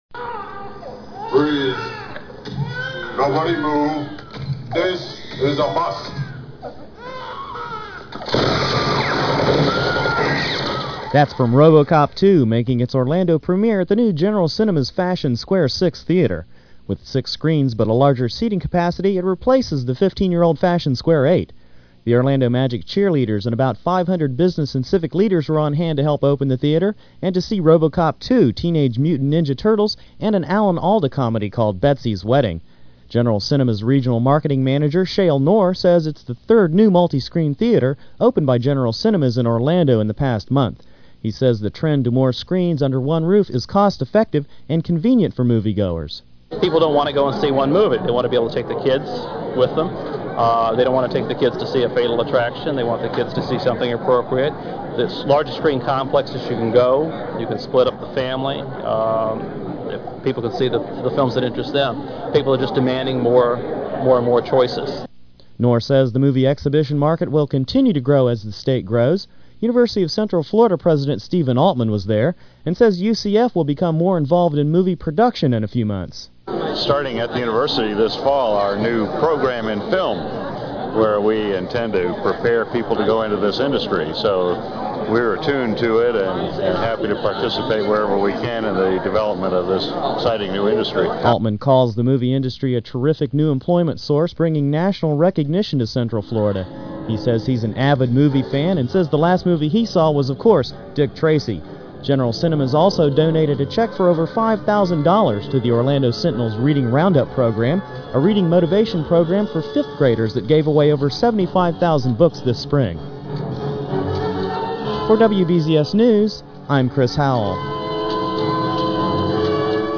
I covered the event from the economic angle. This aired on WBZS-AM.